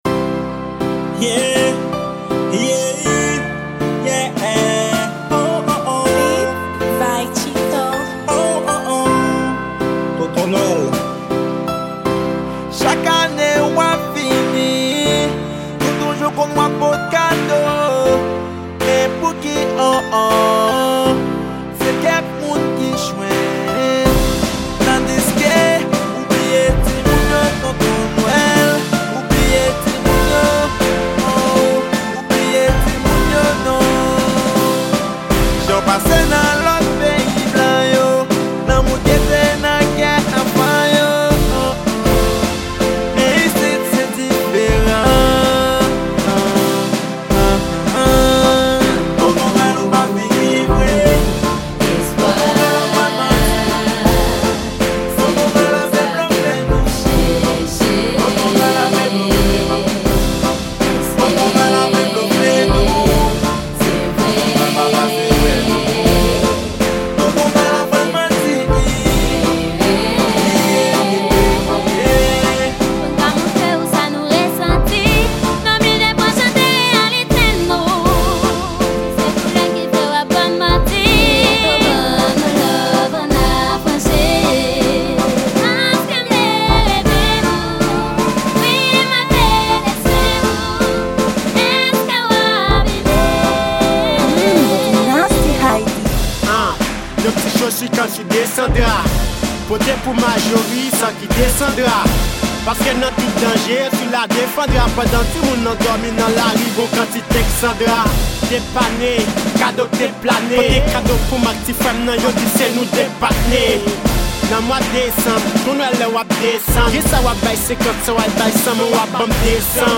Genre: Noel.